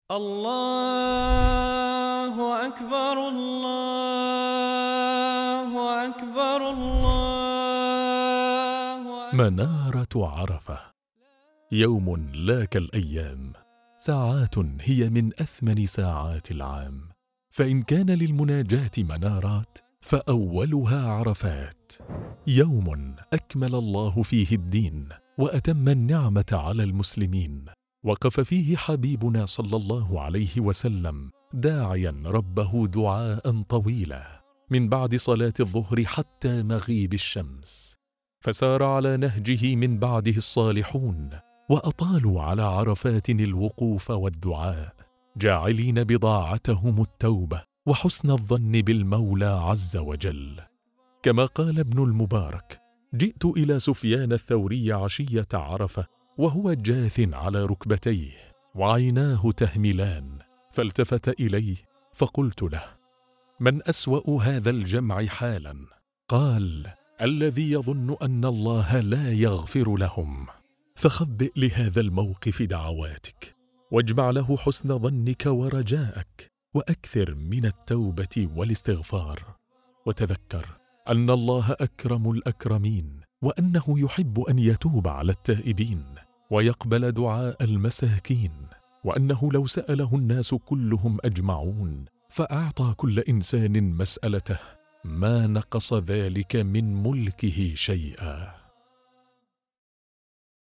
نص موعظي مؤثر يسلط الضوء على فضل يوم عرفة وساعاته الثمينة، ويحث على الإكثار من الدعاء والتوبة والاستغفار فيه. يؤكد على أهمية حسن الظن بالله تعالى وسعة رحمته التي تغفر للجميع، مستشهداً بموقف النبي صلى الله عليه وسلم وهدي السلف الصالح في هذا اليوم العظيم.